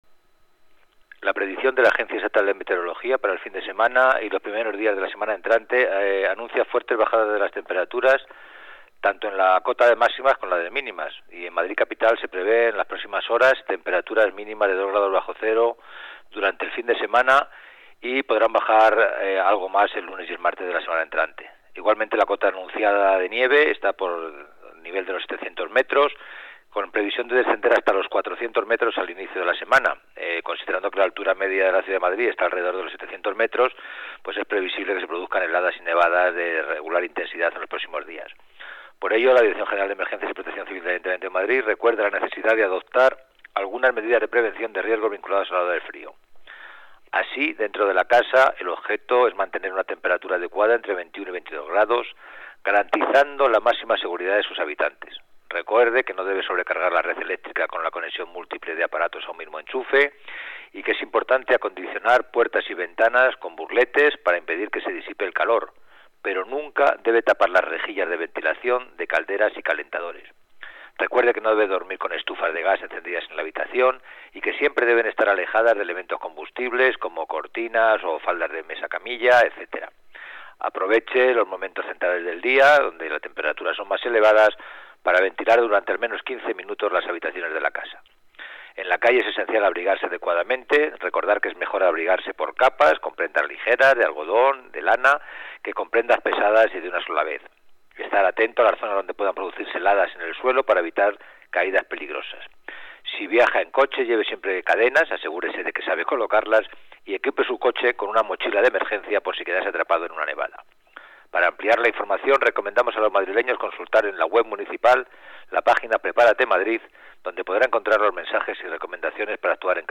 Nueva ventana:Declaraciones director Emergencias, Alfonso del Álamo: recomendaciones contra el frío